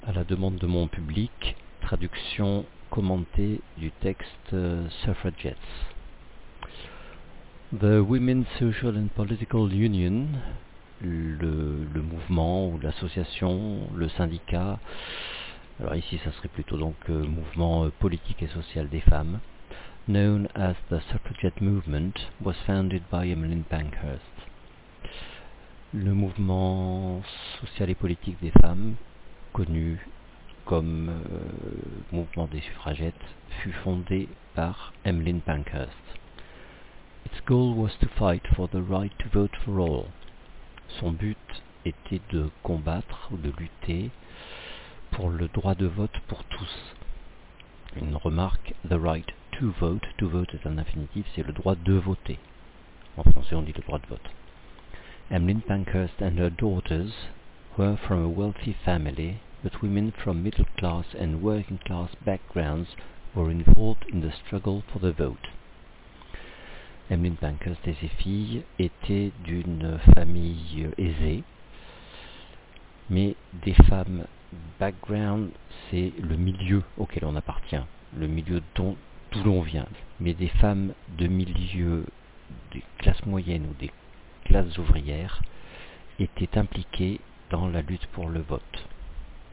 J'ai enregistré une traduction du texte "Suffragettes", à la demande d'une élève.